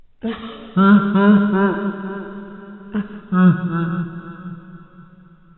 Laugh_Giggle_Girl_8d.wav